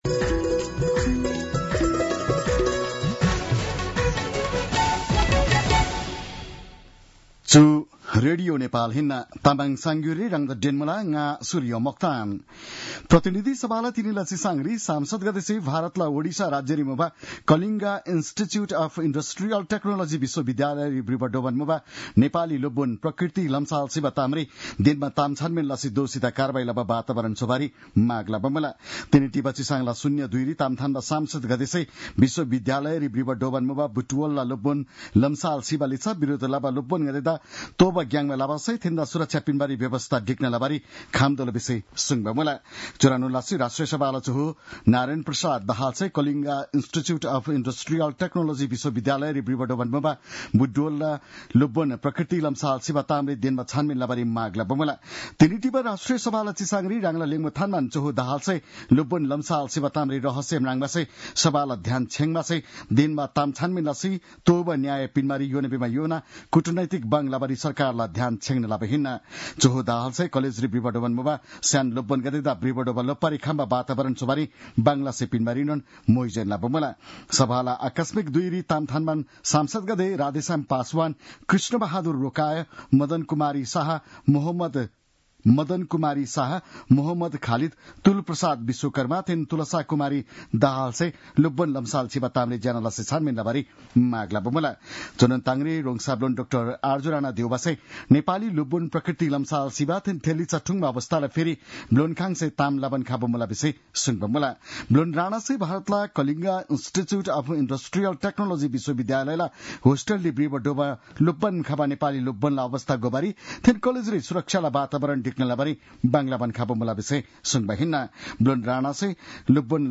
An online outlet of Nepal's national radio broadcaster
तामाङ भाषाको समाचार : ७ फागुन , २०८१